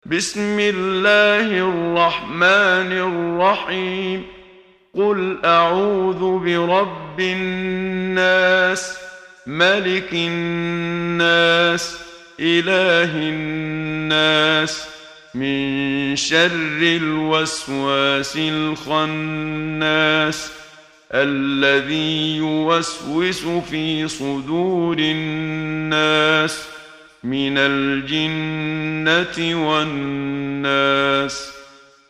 محمد صديق المنشاوي – ترتيل – الصفحة 9 – دعاة خير